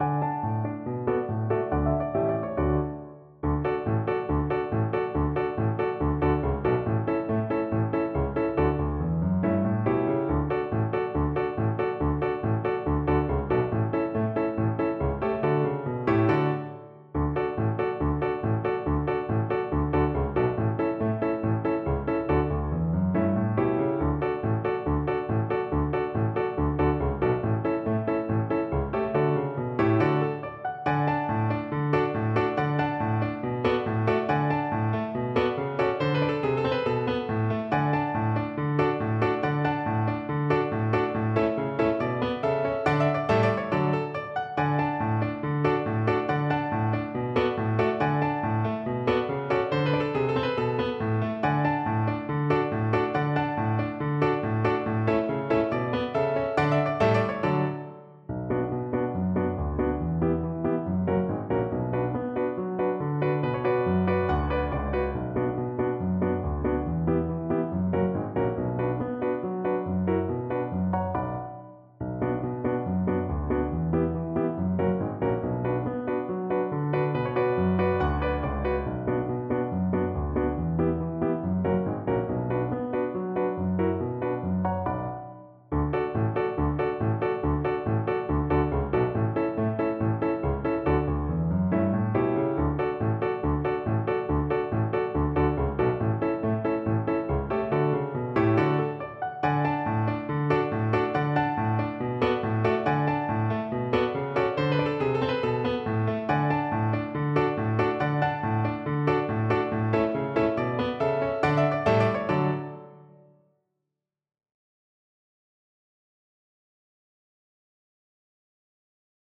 Cello
Traditional Music of unknown author.
D major (Sounding Pitch) (View more D major Music for Cello )
Allegro =c.140 (View more music marked Allegro)
2/4 (View more 2/4 Music)